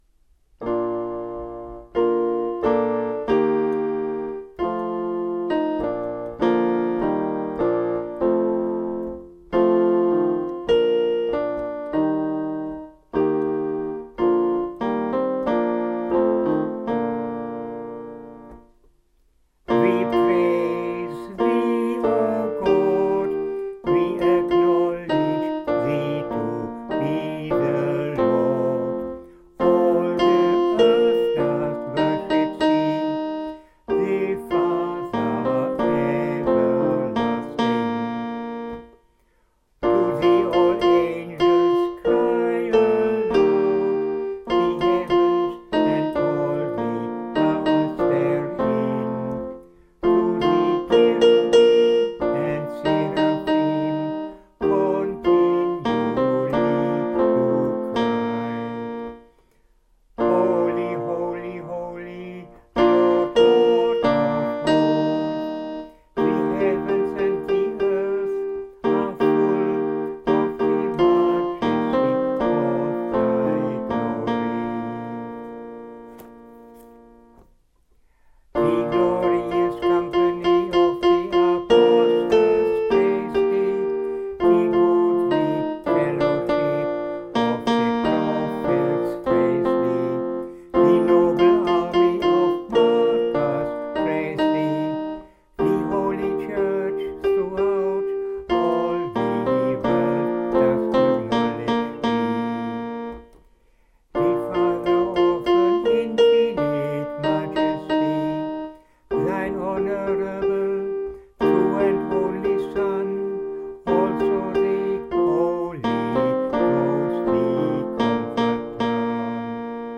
Canticles
te_deum_moravian_canticle_9_sung.mp3